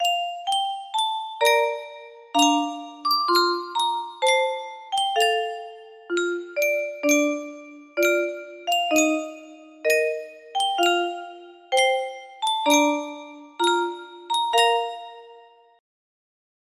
Yunsheng Music Box - Argentina National Anthem 4404 music box melody
Full range 60